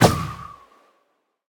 minecraft / sounds / mob / breeze / jump2.ogg
jump2.ogg